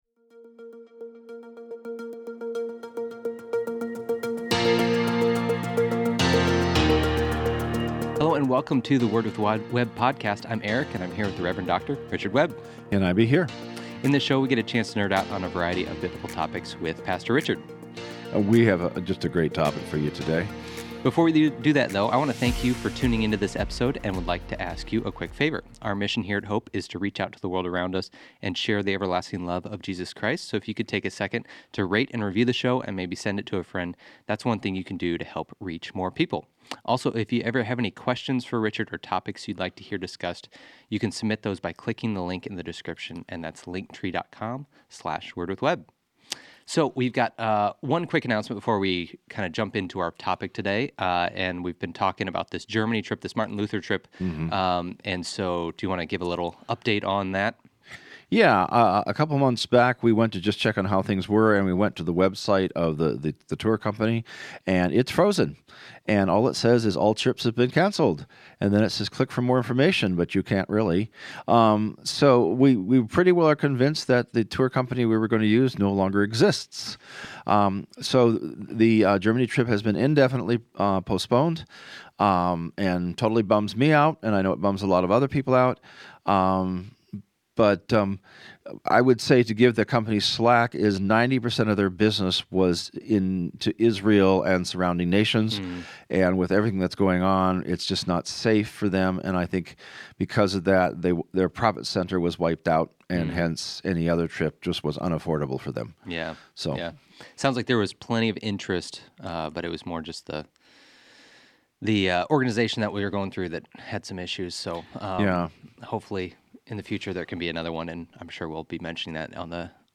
weekly discussions on topics you've always wanted to dig deeper into.